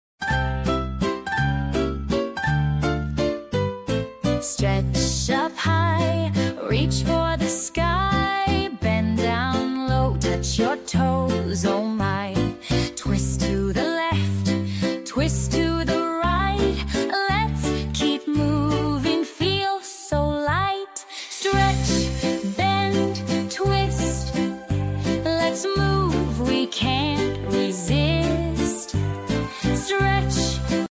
Nursery Rhymes For Toddlers